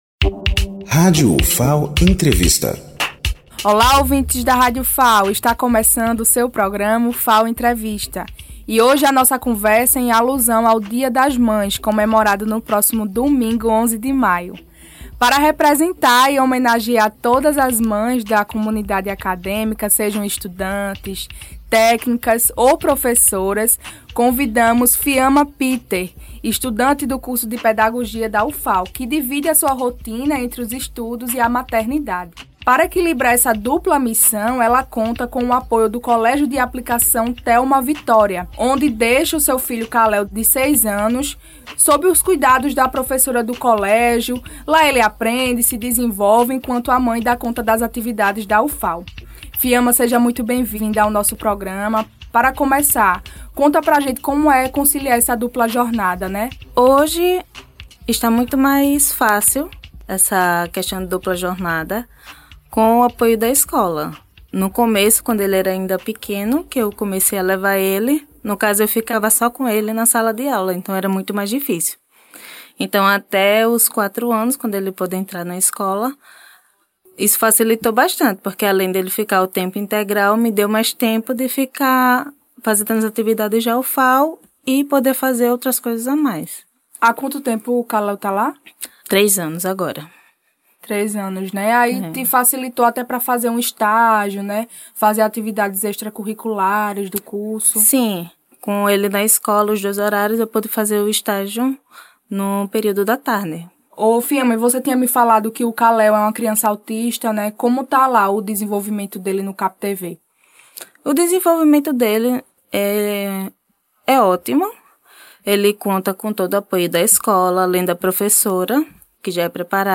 Entrevista
Locução